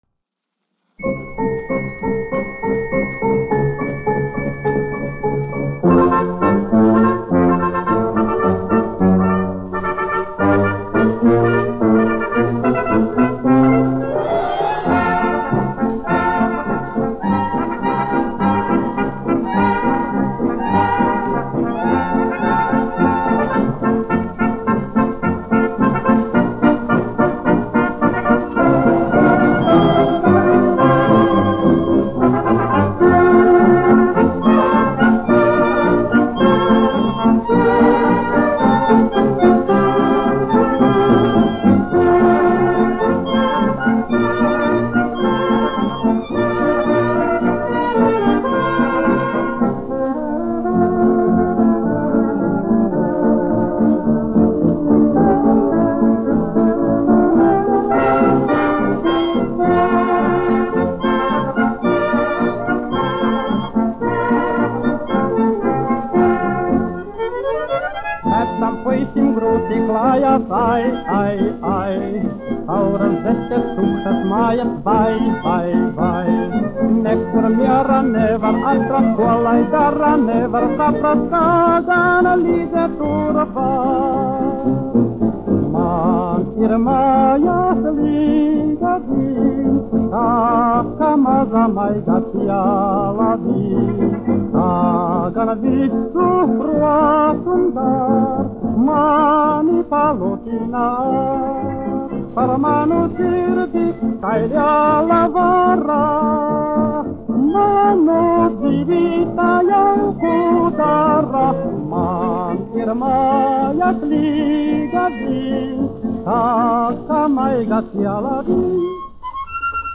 1 skpl. : analogs, 78 apgr/min, mono ; 25 cm
Fokstroti
Populārā mūzika
Latvijas vēsturiskie šellaka skaņuplašu ieraksti (Kolekcija)